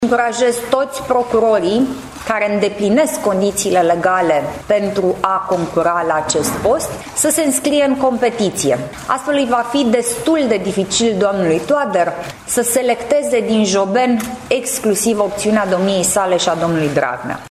Despre procesul de selecţie a viitorului şef al DNA a vorbit joi, 12 iulie, şi senatorul Alina Gorghiu, fost preşedinte PNL, care a încurajat toţi procurorii care îndeplinesc condiţiile legale să candieze la această funcţie: